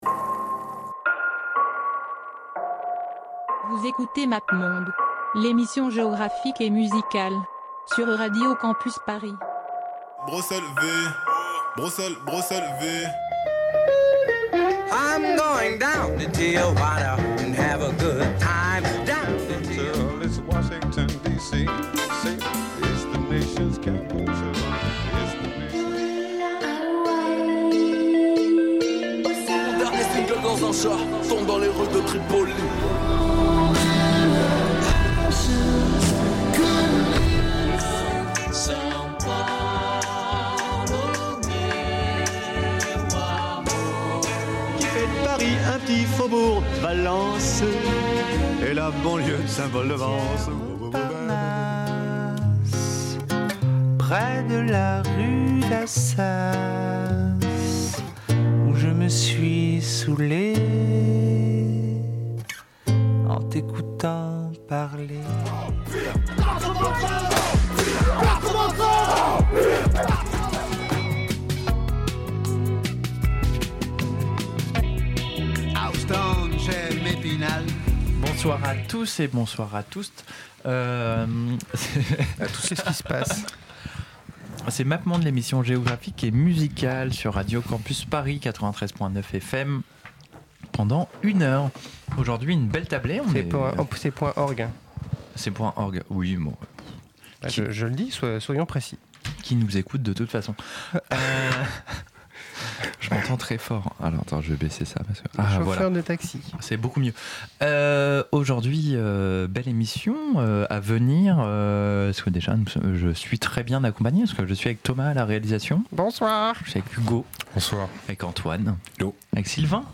La musique de Mélanésie
Au programme, pas mal de chansons et d'enregistrements de mauvaise qualité qui proviennent d'obscurs cassettes de collectionneurs mais aussi des synthés bien kitsch, des rythmes endiablés et toujours des influences américaines.